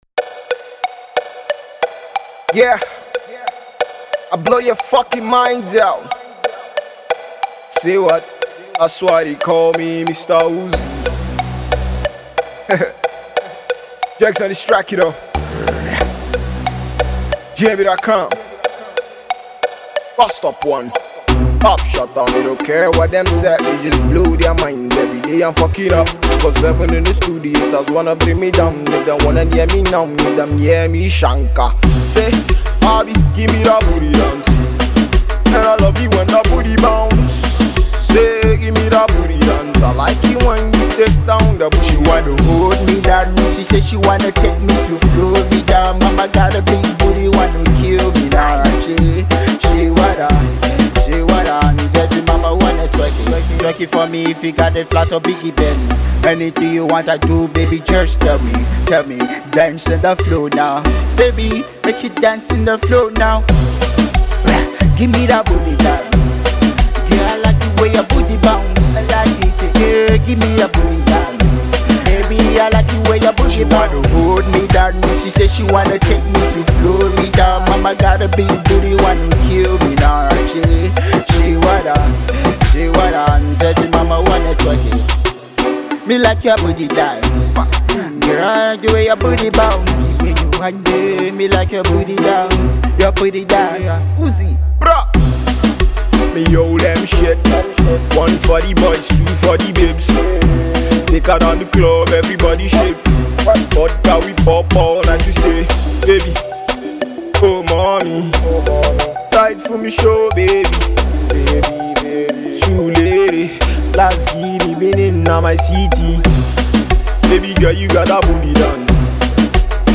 Reggaeton inspired